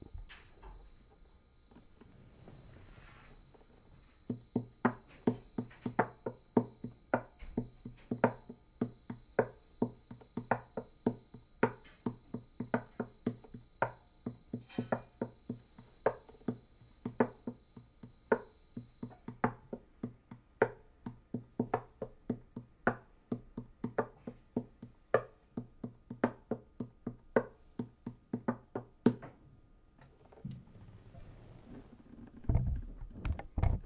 描述：错综复杂的鼓机节拍，在Ableton Live中被编程和折磨。
标签： 节奏 鼓机 毛刺 击败
声道立体声